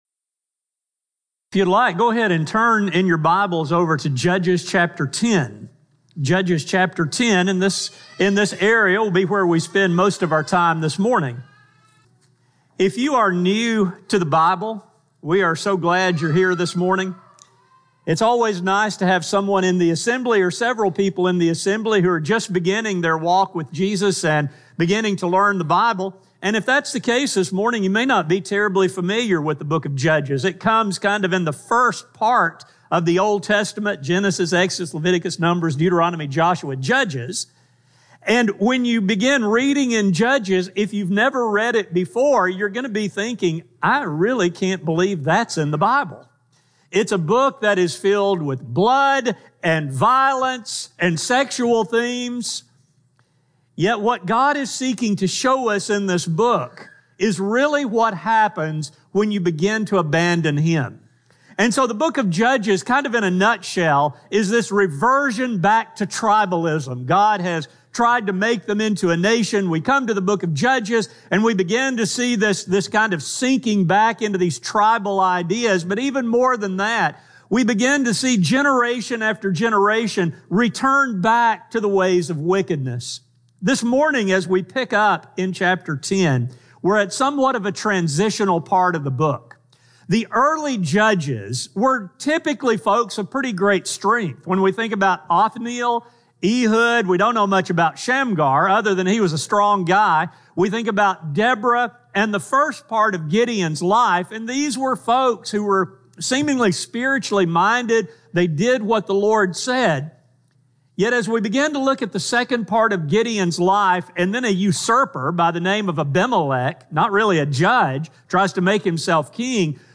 General Service: Sun AM Type: Sermon